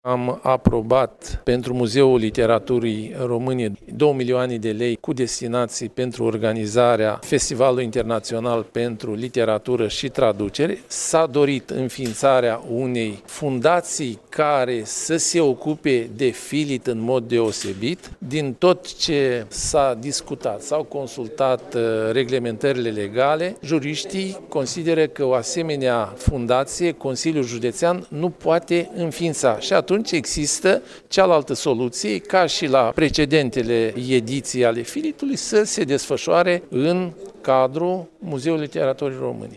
În replică, președintele interimar al CJ Iași, Victorel Lupu, a precizat că la ultima ședință au fost aprobate două milioane de lei pentru Muzeul Literaturii Române.